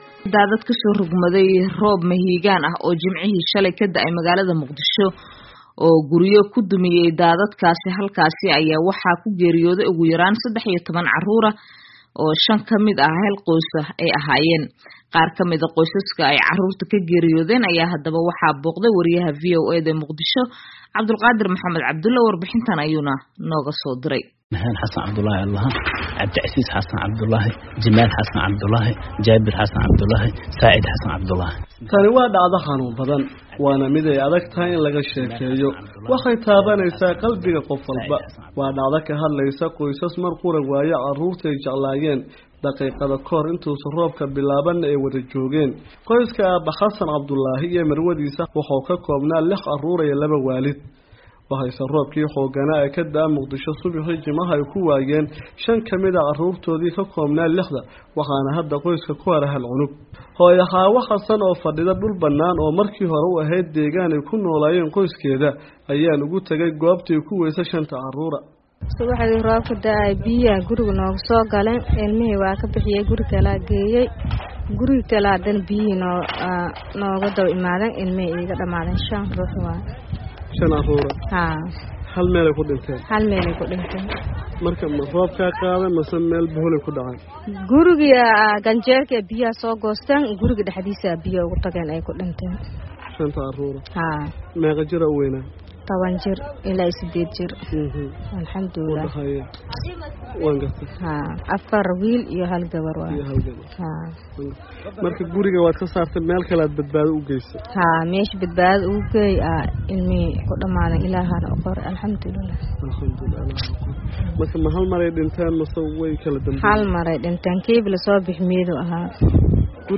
MOGADISHU —